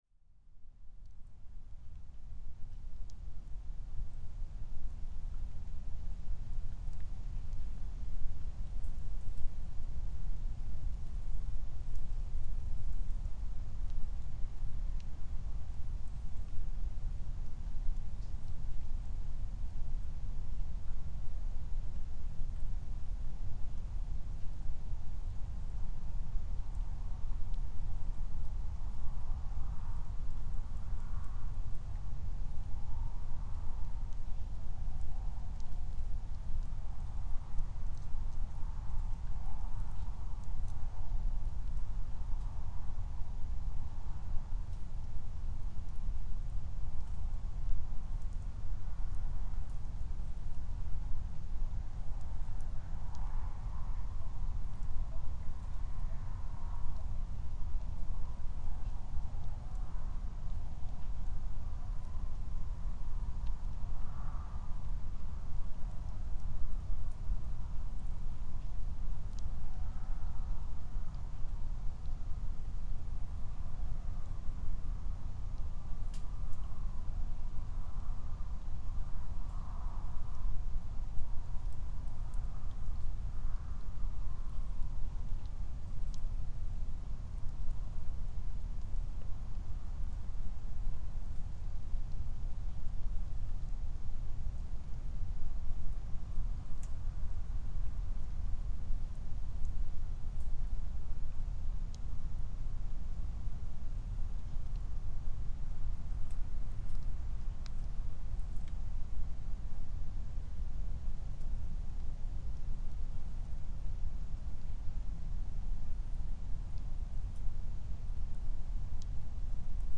It is mainly a silence. But this is just a normal natural Icelandic soundscape in calm winter weather, so I have a lot of stuff like that in my collection which I think should be published in this blog At least I can tell. it is very good to fall asleep from recording like this. This one was made overnight on 4th of March 2023 and the time is around 5 o’clock.
But anyway it is a traffic noise in this recording. Mainly from the main road no:1 for about 5km away and one car will pass by the recording location, 250 meters away.
It gave me the opportunity not to use low pass filter LPF, which gave me the ability to listen for a variety of low-frequency sounds that I’ve often encountered on this recording site before but has been hard to explain.
Two foxes can be heard calling. Rock ptarmigan, ducks and swans in the distance.